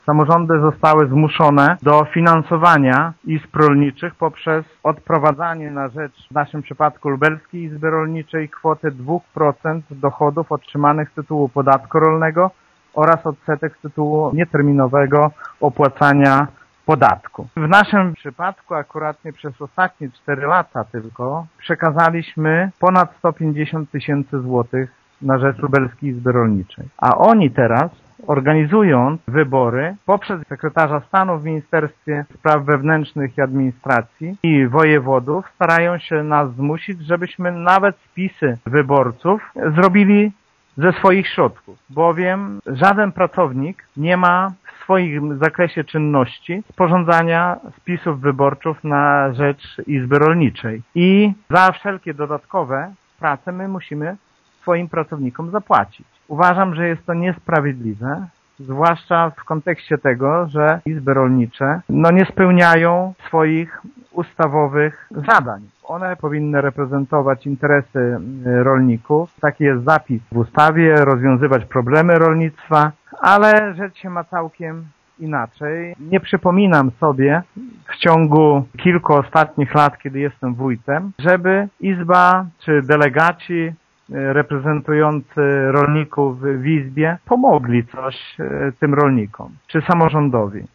Wójt Szopiński podkreśla, że samorządy i tak przekazują środki na utrzymanie izb rolniczych na mocy ustawy z 1995 roku: